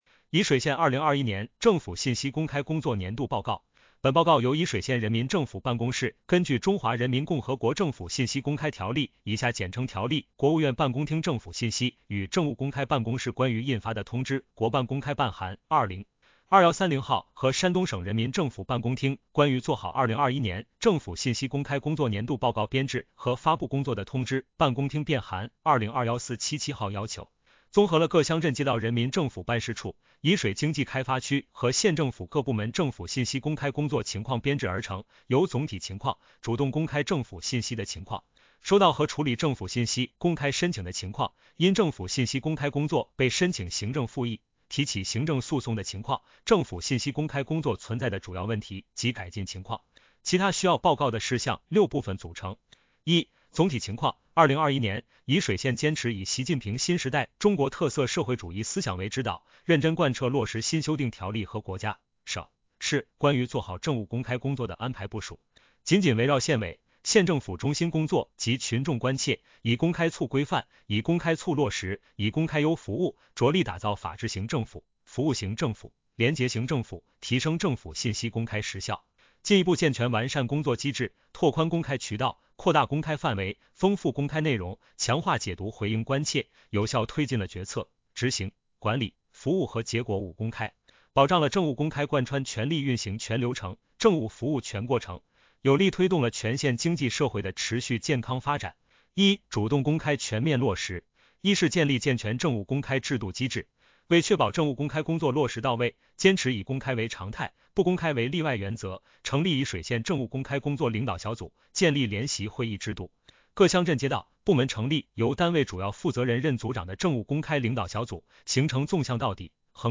全文朗读